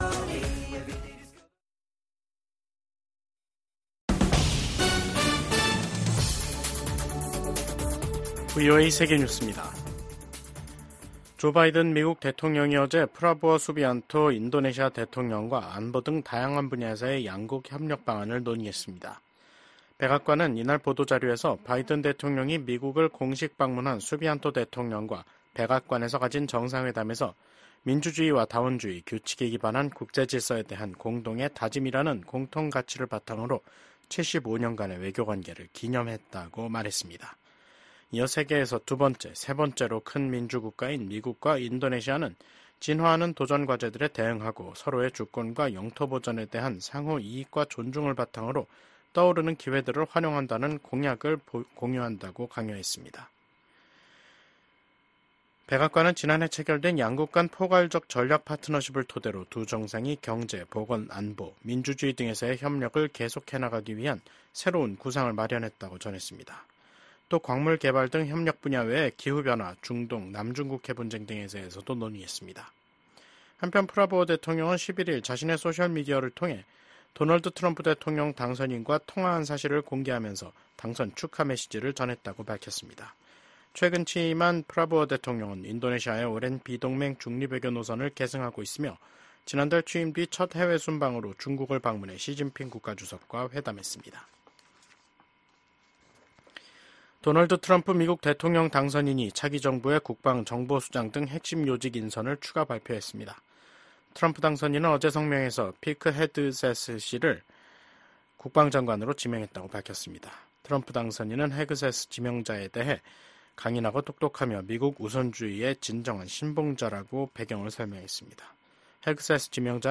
VOA 한국어 간판 뉴스 프로그램 '뉴스 투데이', 2024년 11월 13일 3부 방송입니다. 미국 백악관이 북한군의 러시아 파병을 공식 확인했습니다. 최소 3천명이 러시아 동부 전선에 파병됐으며 훈련 뒤엔 우크라이나와의 전투에 배치될 가능성이 있다고 밝혔습니다.